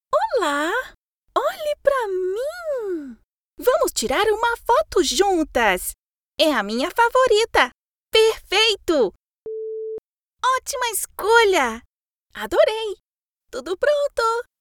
游戏角色【少女童声】